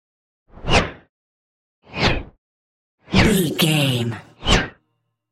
Whoosh bright fast x4
Sound Effects
Atonal
Fast
bright
futuristic
whoosh